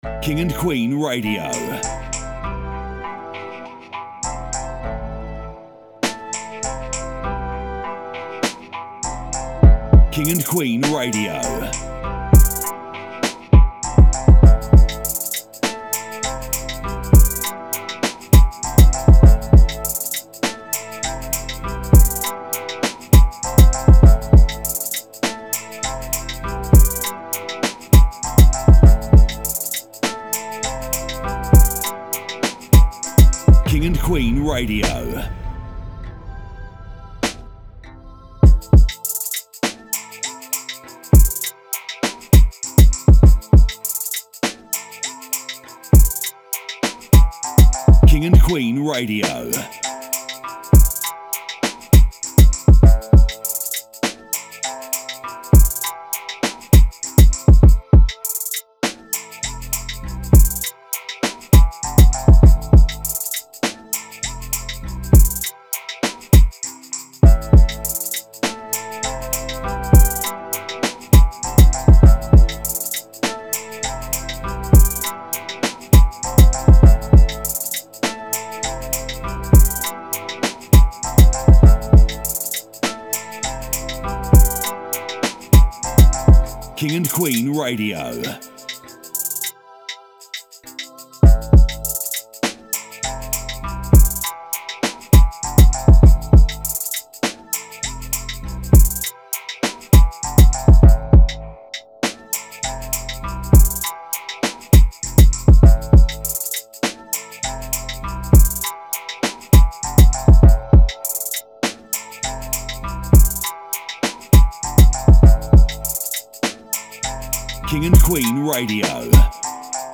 BPM 100 1 File (7.14mb)
Category: Beats